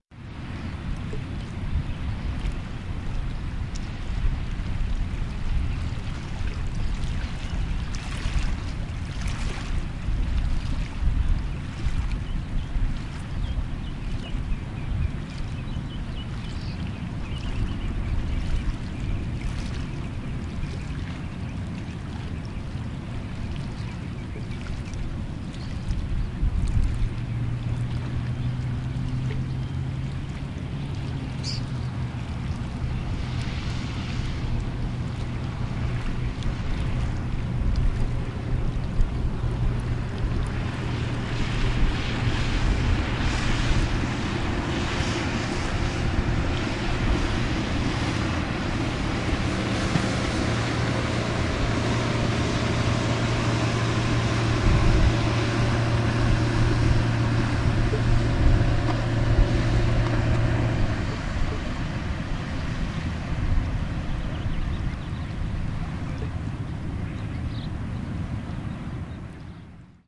迅雷艇
描述：在长滩海洋体育场，一艘动力艇拉着一个滑水运动员的现场录音。
Tag: 字段 汽艇 摩托艇 记录 波浪